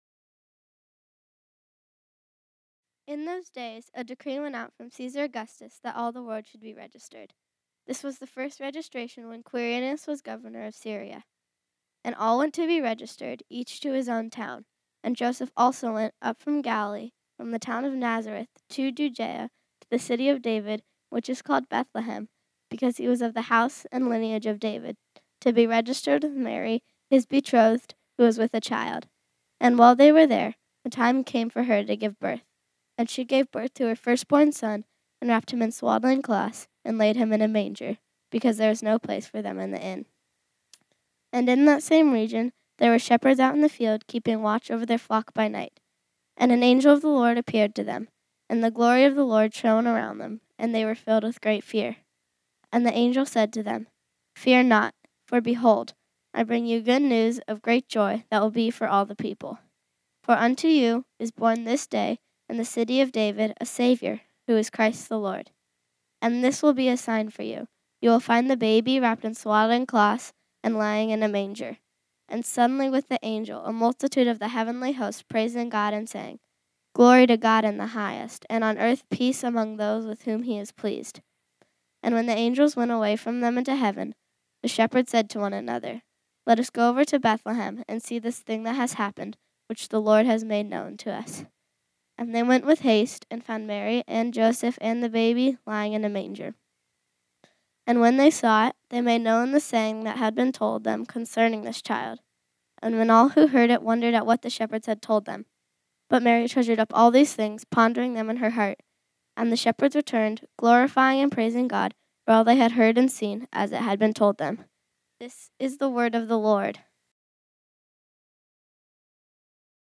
This sermon was originally preached on Sunday, December 15, 2019.